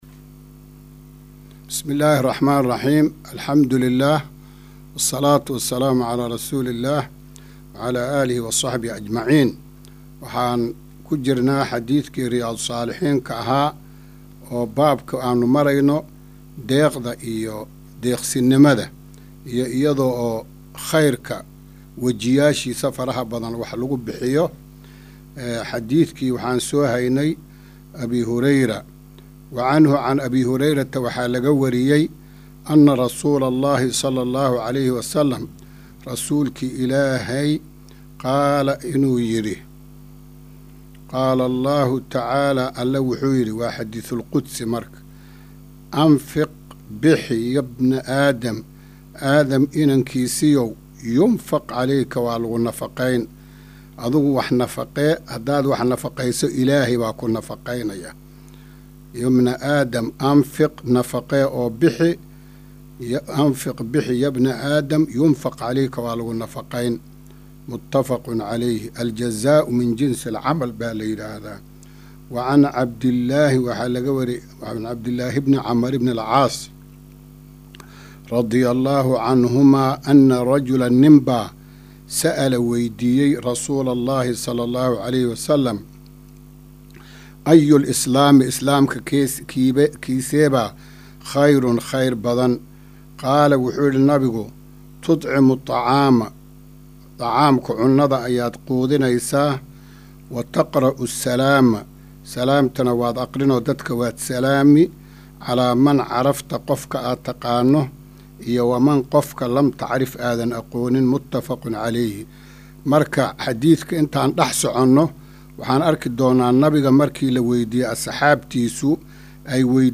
Maqal- Riyaadu Saalixiin – Casharka 30aad